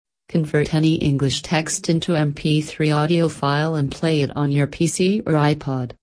Convert Text To Speech
• It offers natural-sounding voices for text to speech conversion.